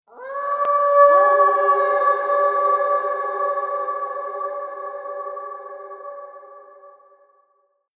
دانلود آهنگ مزرعه 28 از افکت صوتی طبیعت و محیط
دانلود صدای مزرعه 28 از ساعد نیوز با لینک مستقیم و کیفیت بالا
جلوه های صوتی